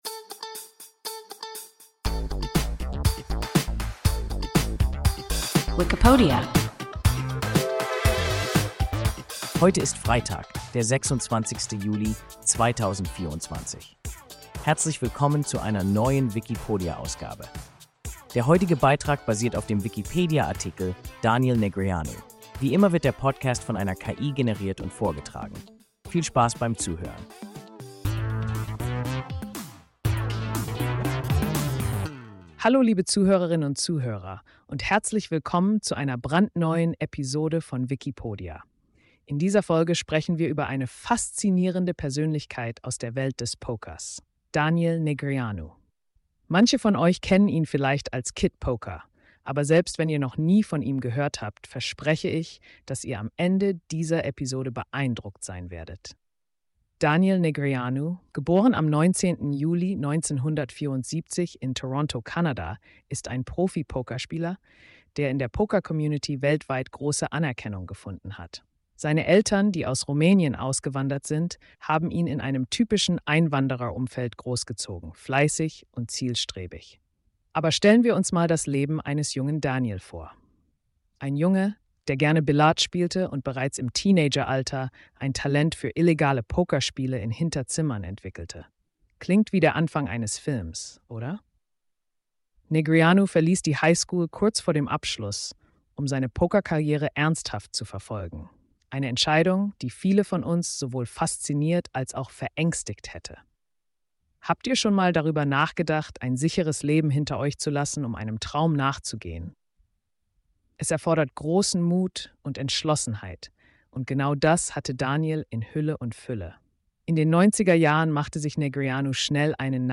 Daniel Negreanu – WIKIPODIA – ein KI Podcast